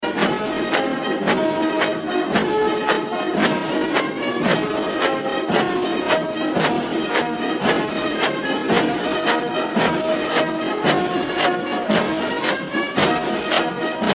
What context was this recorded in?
The countdown to the military parade at Tiananmen Square in Beijing, China on May 3, 2023 has begun#Parade